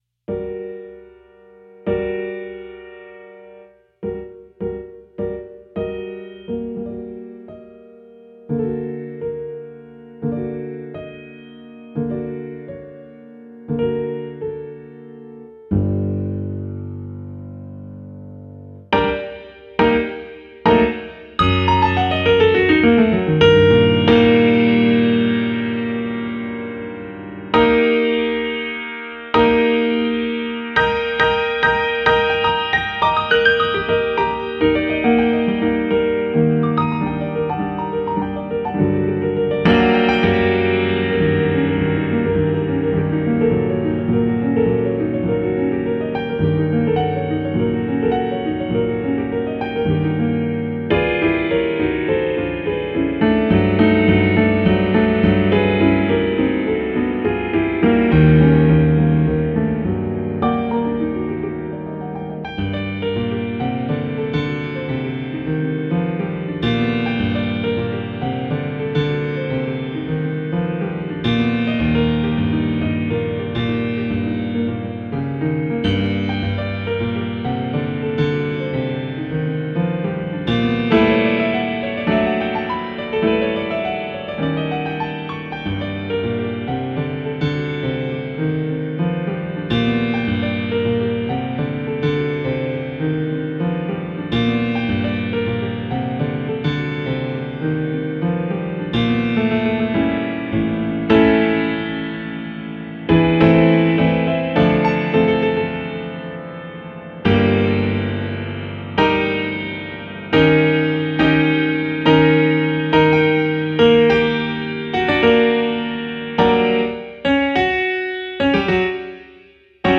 neo-classical piano